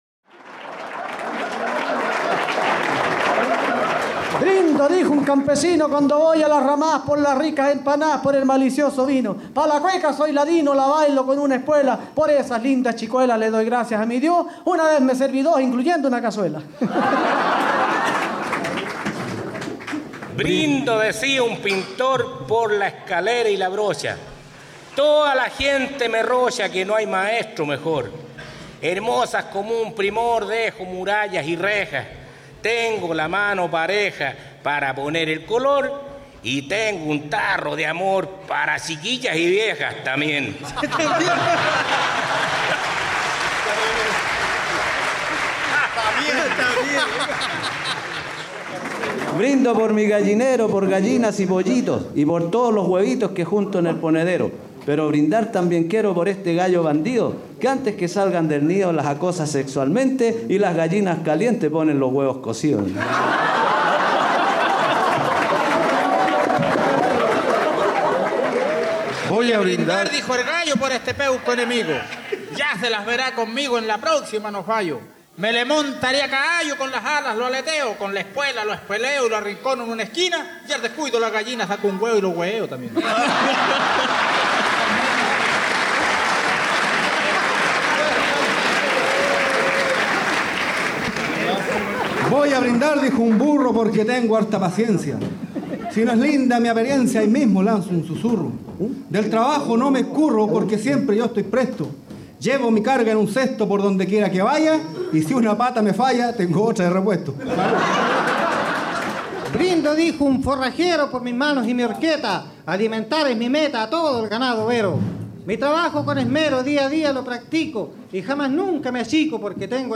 Paya improvisada durante el Encuentro de payadores en Casablanca y Portezuelo, 1996-97.
Folklore